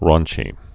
(rônchē, rän-)